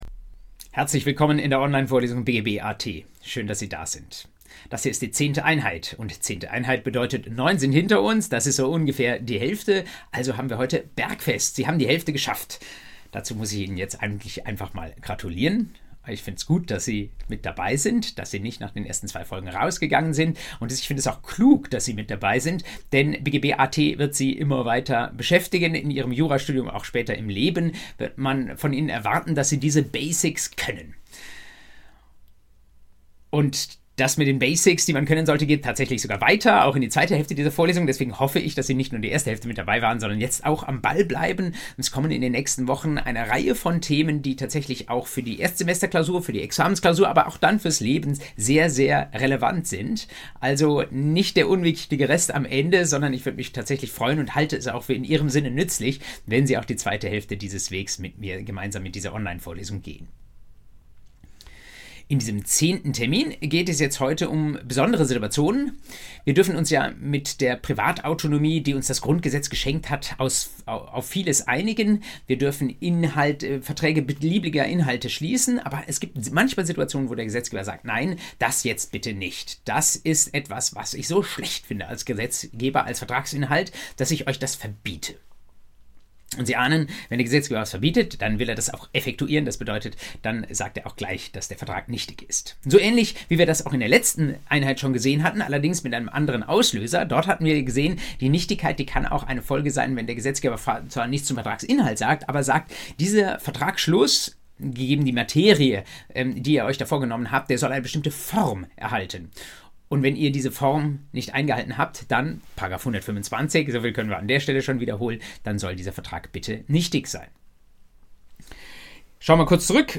BGB AT Folge 10: Verbotene Verträge ~ Vorlesung BGB AT Podcast